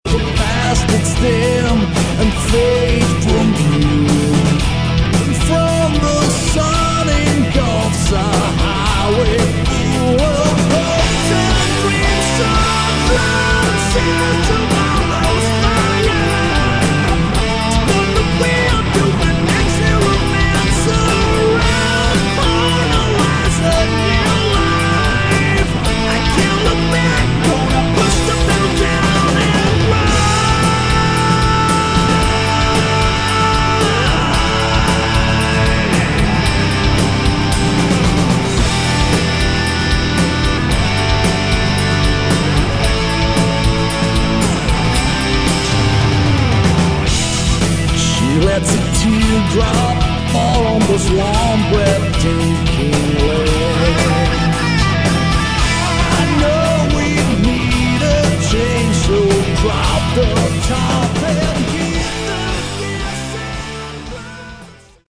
Category: Melodic Rock
bass, vocals
keyboards
drums, percussion
guitars